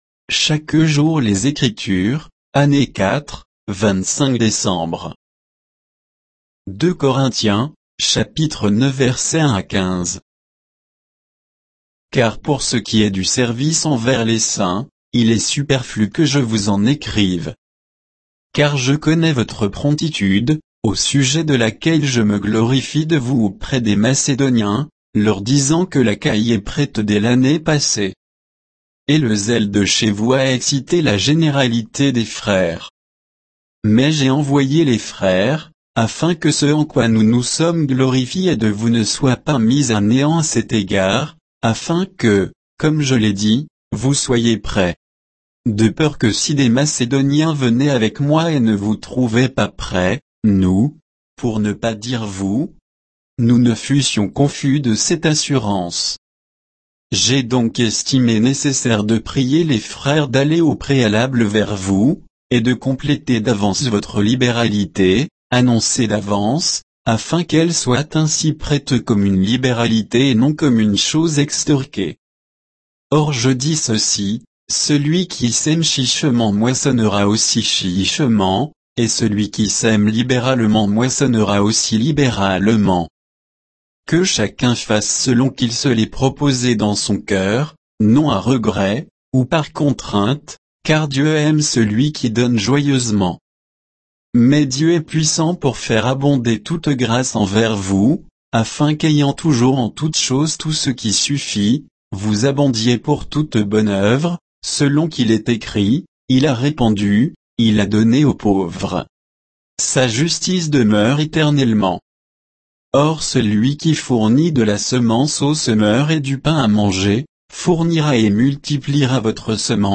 Méditation quoditienne de Chaque jour les Écritures sur 2 Corinthiens 9